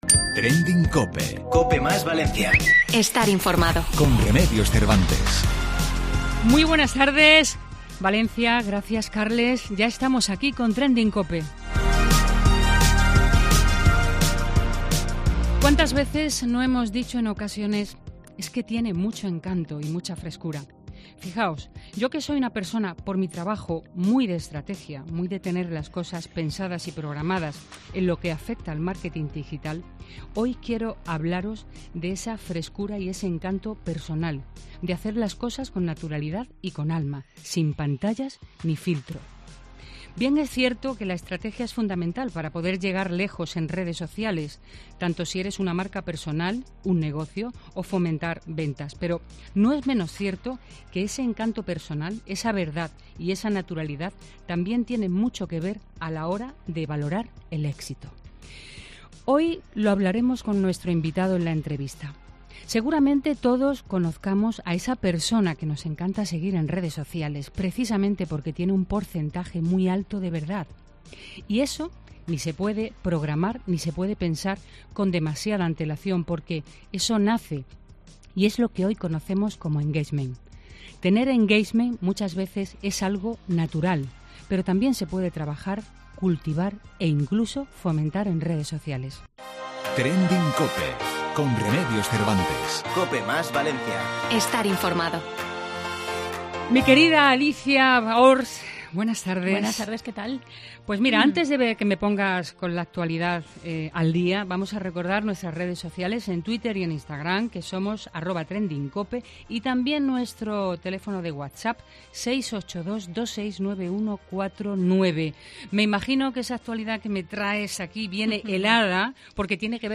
El exjugador de baloncesto visita el programa de Remedios Cervantes, TrendingCOPE, para hablar de cómo se desenvuelve en el mundo digital